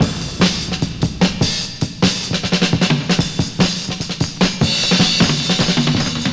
Original creative-commons licensed sounds for DJ's and music producers, recorded with high quality studio microphones.
151 Bpm Drum Loop Sample F Key.wav .WAV .MP3 .OGG 0:00 / 0:06 Free drum beat - kick tuned to the F note.
151-bpm-drum-loop-sample-f-key-wFu.wav